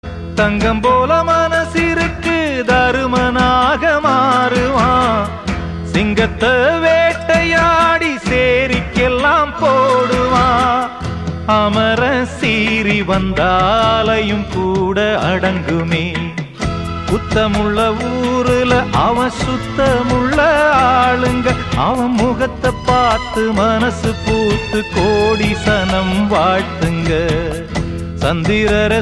full of action, drama, and intense emotions